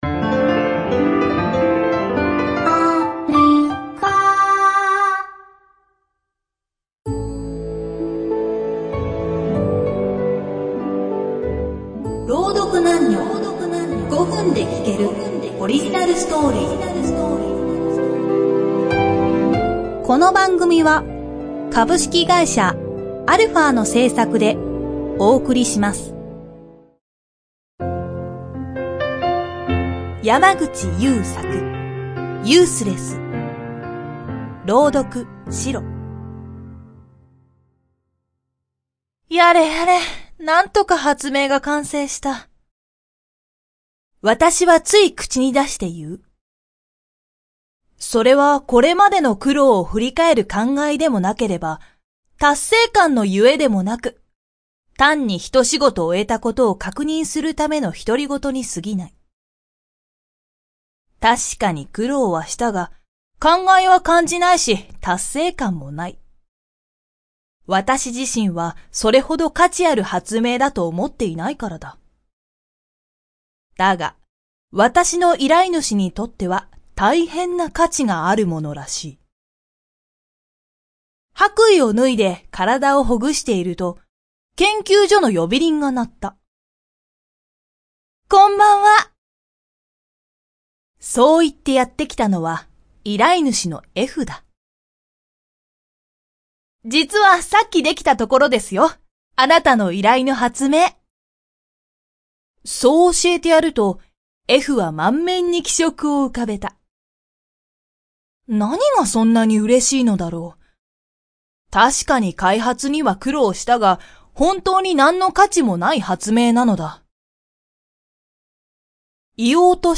roudoku_28.mp3